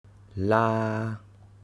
ToneMidLowFallingHighRising
Phoneticlaalàalâaláalǎa